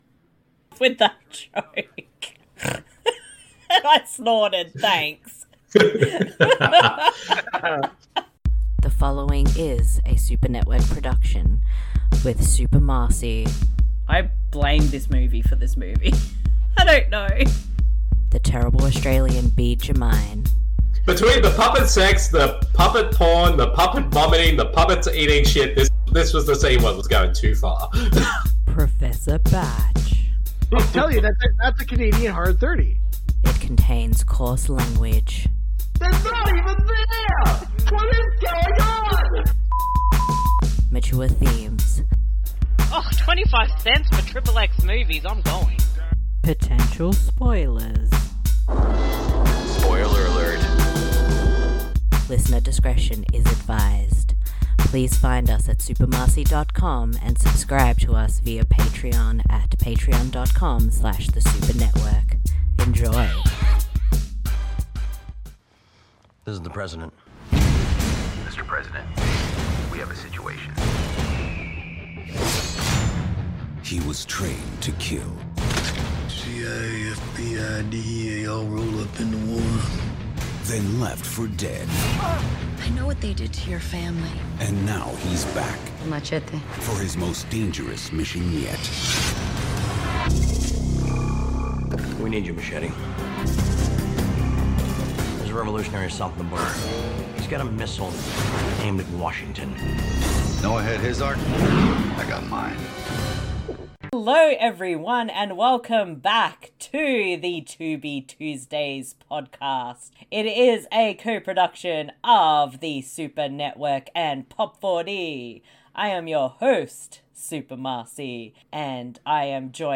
DISCLAIMER: This audio commentary isn’t meant to be taken seriously, it is just a humourous look at a film.